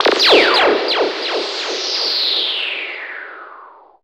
LOFI LASER.wav